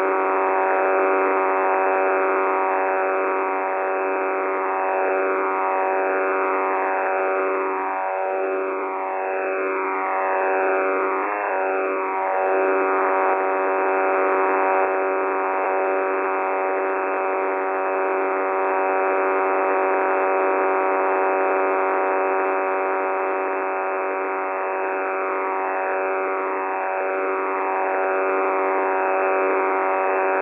UNID Signal Winding Up
This signal appears to be much different from what was originally spotted on 8992 kHz.  This is what was determined to be located in Rufisque, Senegal with TDoA.